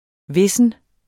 Udtale [ ˈvesən ]